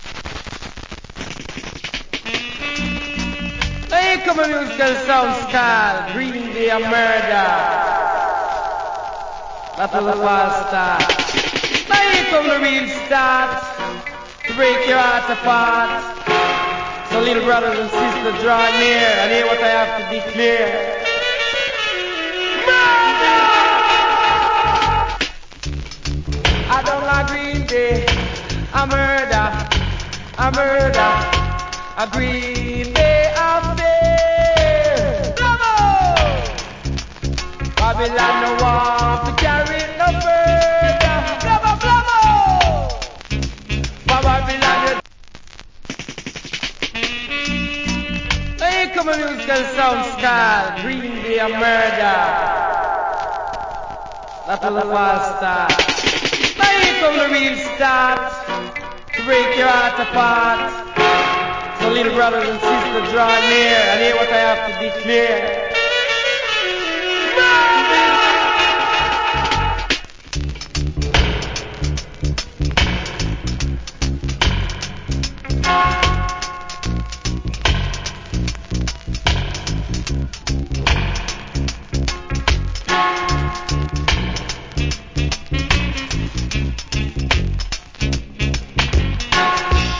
Condition VG+(WOL) / VG+ *PRESS NOISE.
コメント Killer DJ.
Nice Dub.